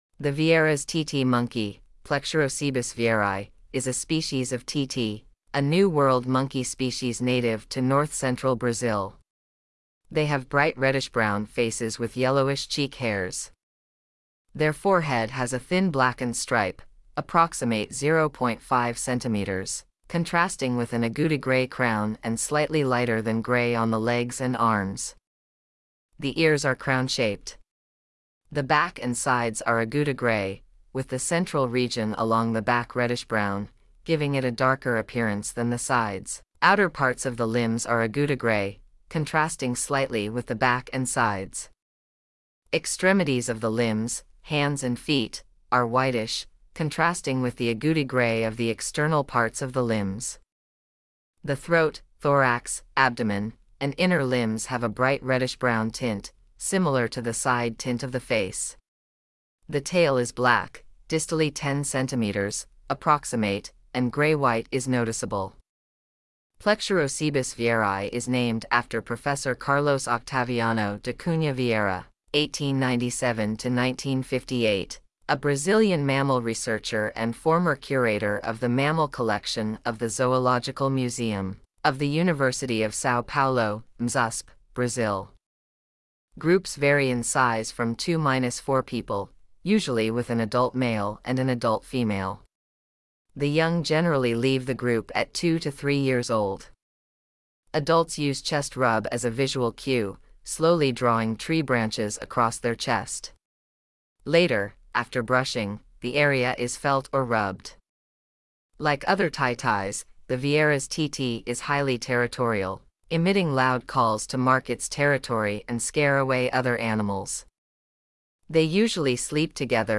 Vieira’s Titi
• Like other titis, the Vieira’s Titi is highly territorial, emitting loud calls to mark its territory and scare away other animals.